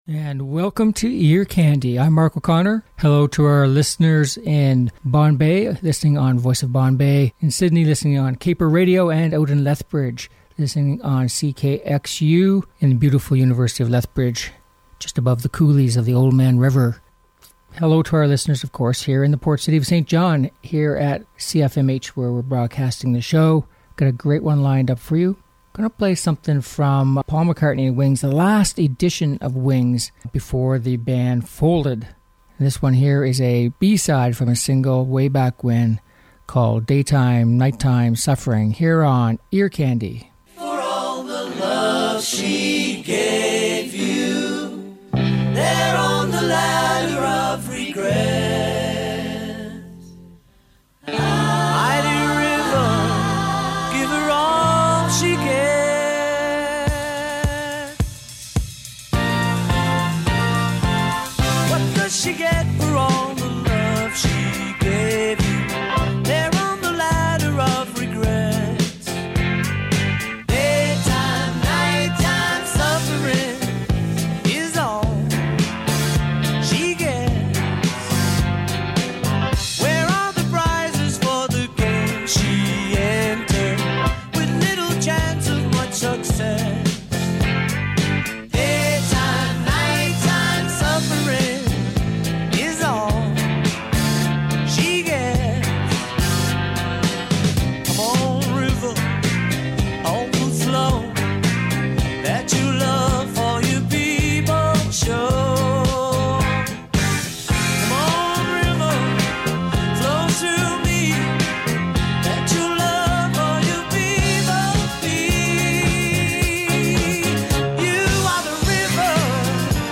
Uptempo Pop and Rock Songs